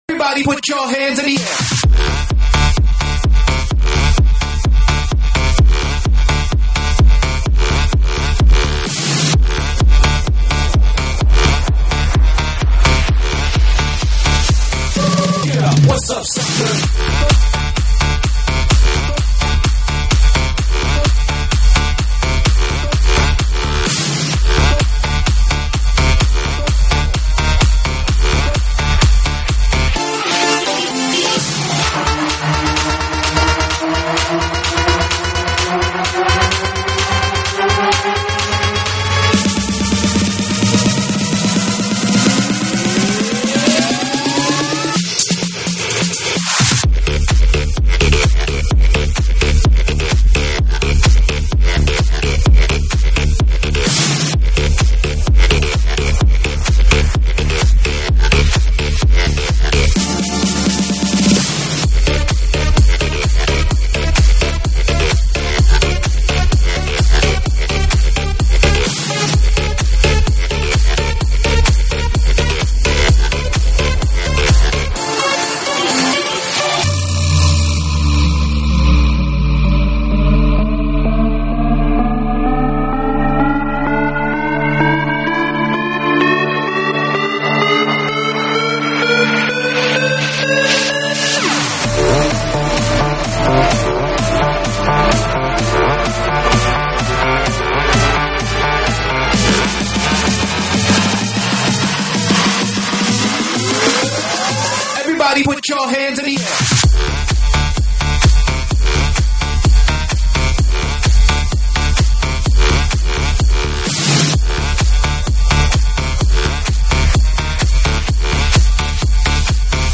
英文舞曲